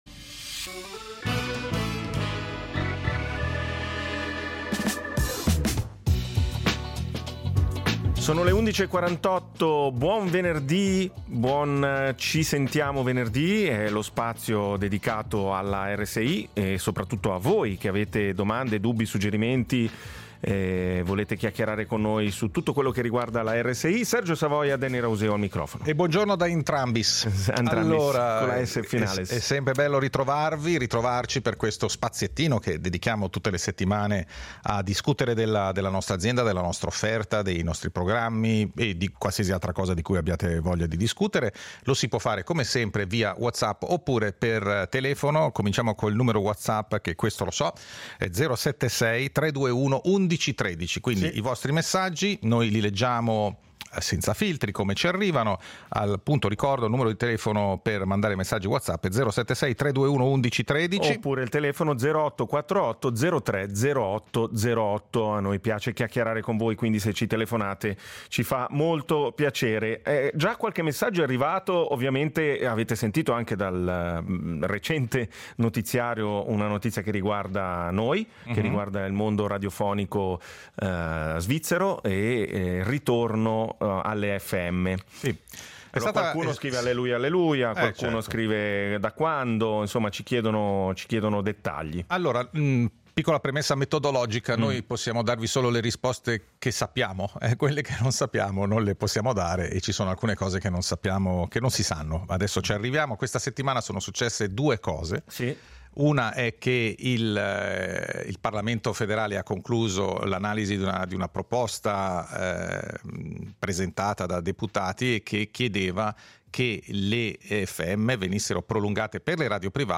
Ogni venerdì, dalle 11.45 alle 12.30, “Ci sentiamo venerdì” è il tavolo radiofonico dove ci si parla e ci si ascolta.